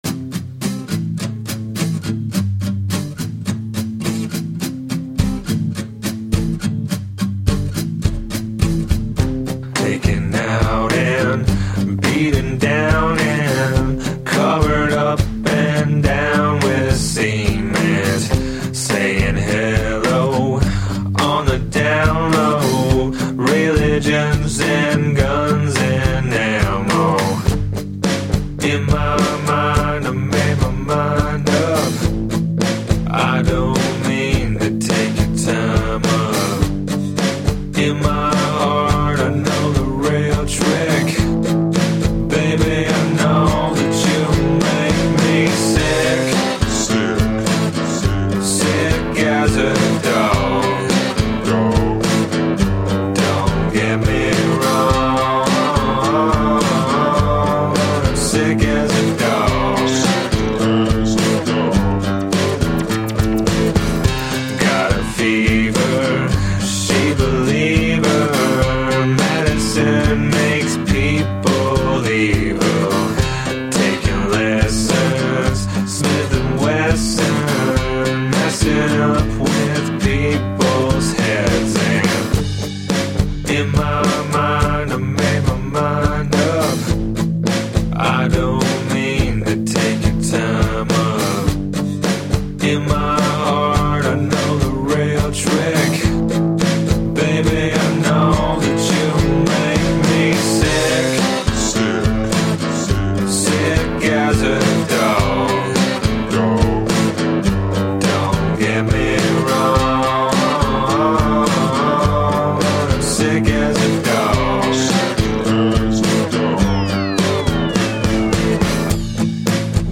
Brilliantly sardonic indie rock.